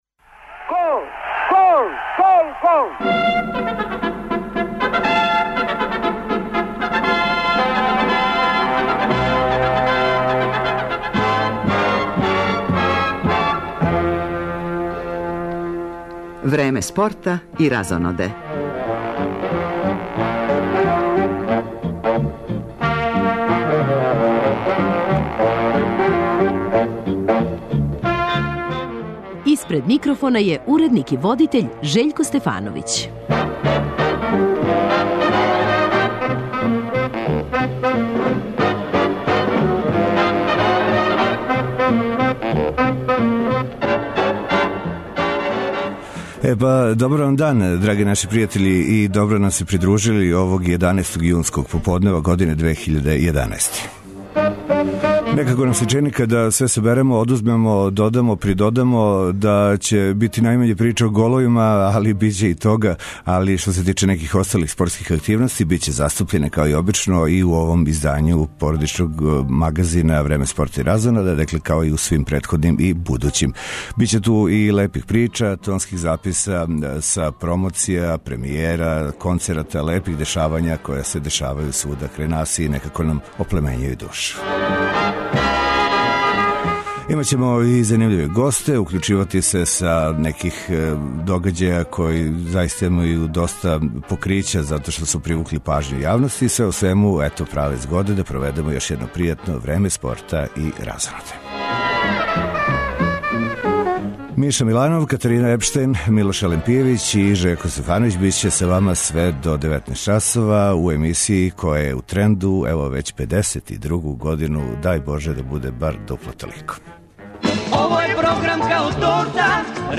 Директор Београдске филхармоније Иван Тасовац, поред завршетка сезоне, говори и о рођендану куће на чијем је челу, као и о светском дану музике.